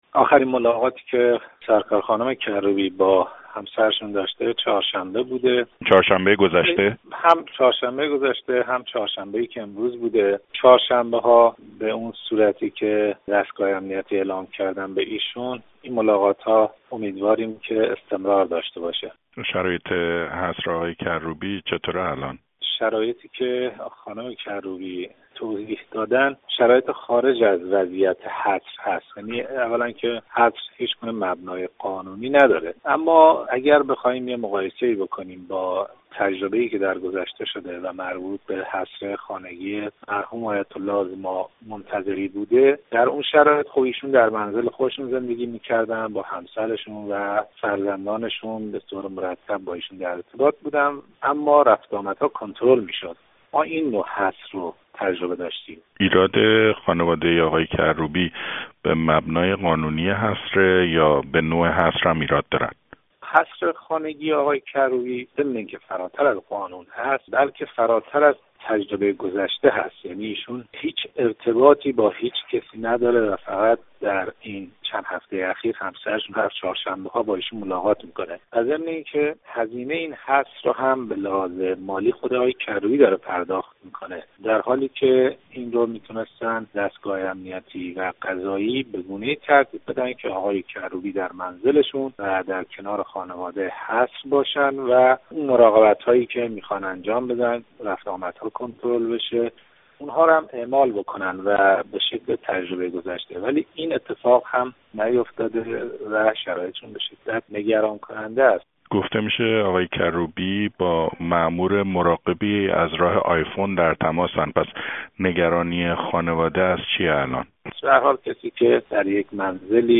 اسماعيل گرامی مقدم، مشاور مهدی کروبی در گفت وگويی با راديو فردا «حصر خانگی» مهدی کروبی را «سليقه‌ای» دانسته و گفته است شرايط آقای کروبی در «حصر» نگران کننده است.